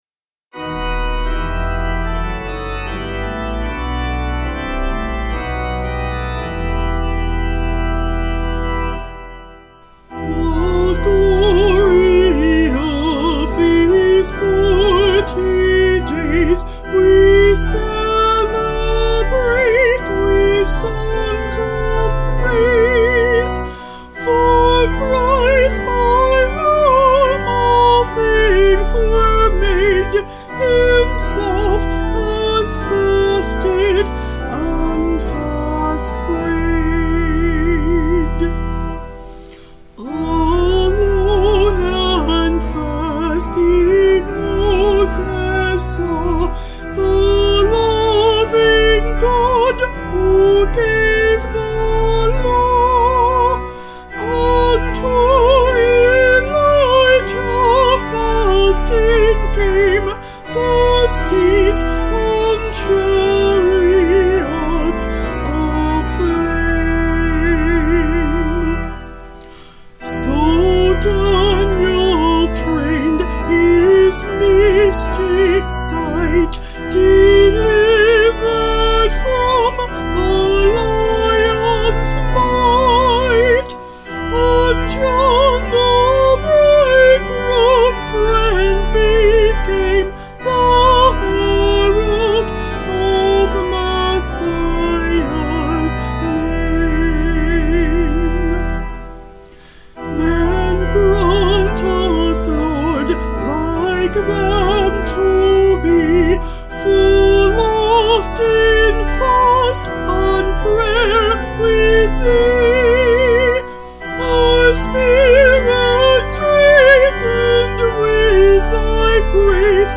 5/Em
Vocals and Organ   176.8kb Sung Lyrics